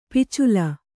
♪ picula